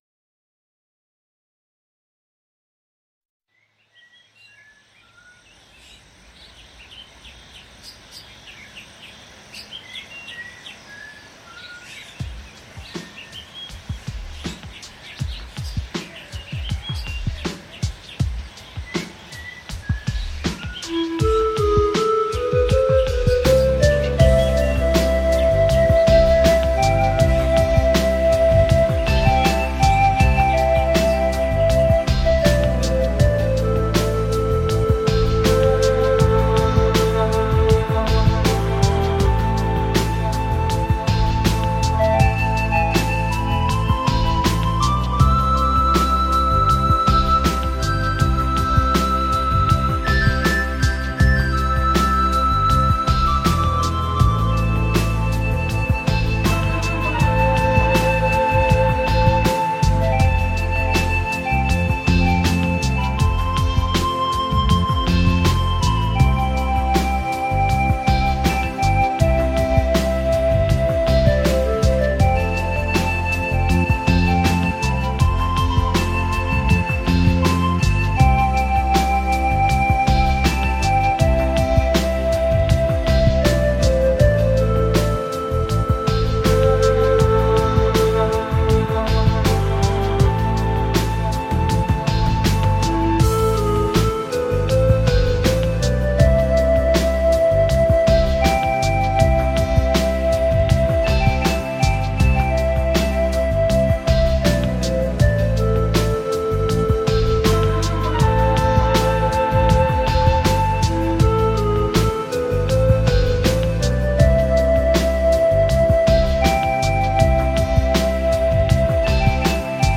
STRUMENTALE